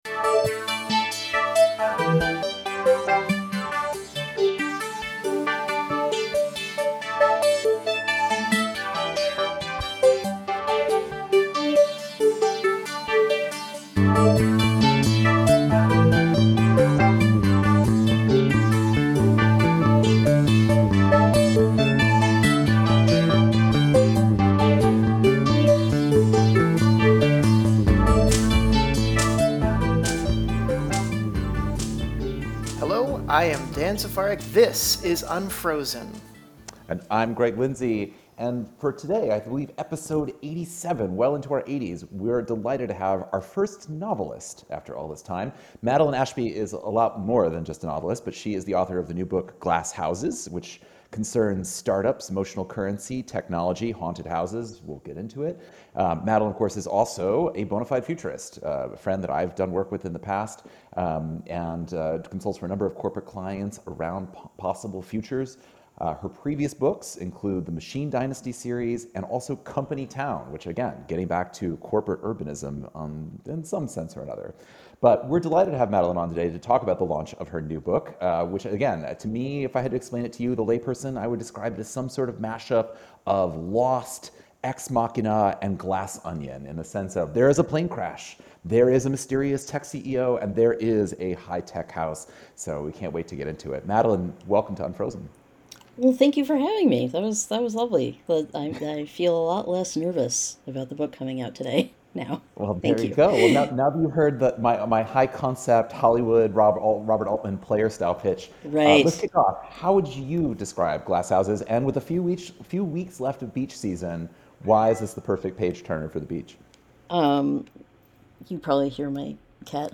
The first Unfrozen interview with a novelist takes us on a journey to desert islands, bland design-hotel furniture, evil architecture tropes, and much more.